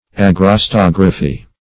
Search Result for " agrostography" : The Collaborative International Dictionary of English v.0.48: Agrostography \Ag`ros*tog"ra*phy\, n. [Gr.
agrostography.mp3